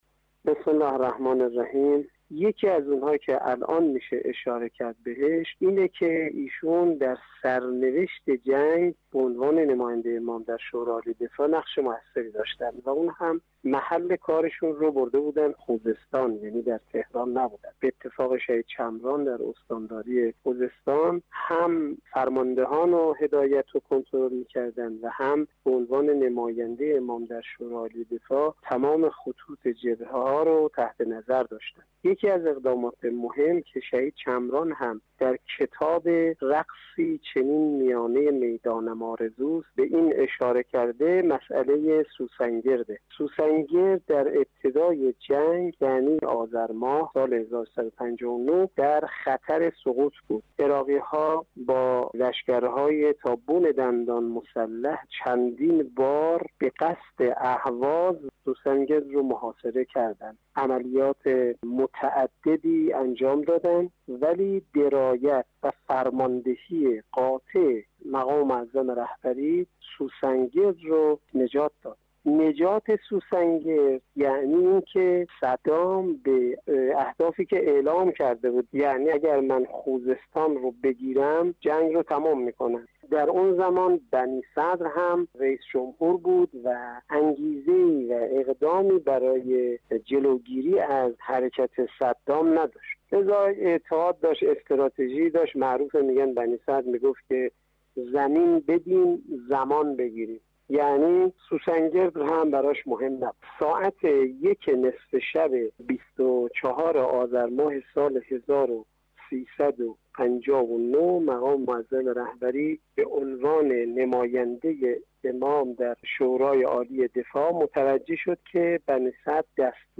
گزارشی مستند از نقش بی بدیل رهبر انقلاب در دفاع مقدس